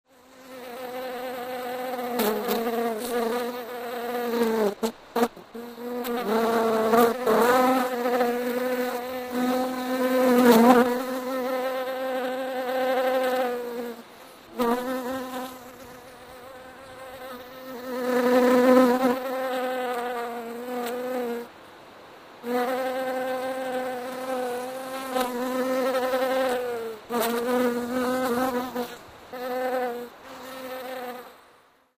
На этой странице собраны натуральные звуки шмелей — от монотонного жужжания до активного полета между цветами.
Звук касания шмеля о цветок в поисках нектара